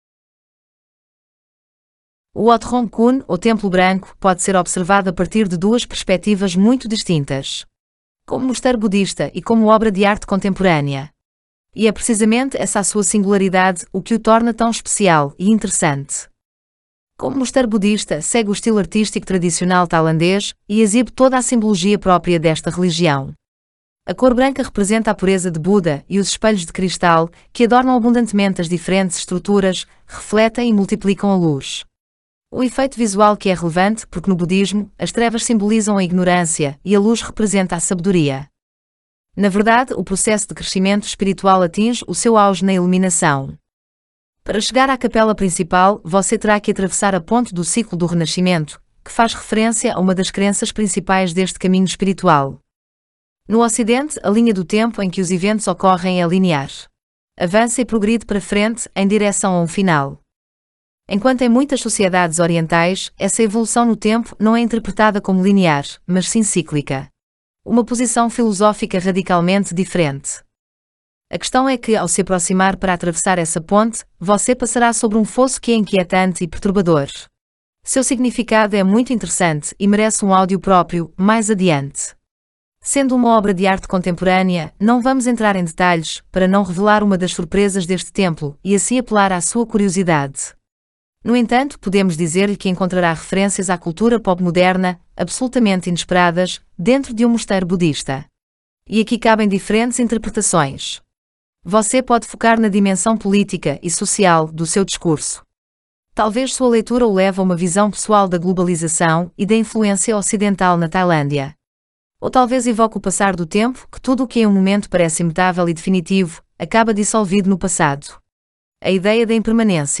Como são os audioguias?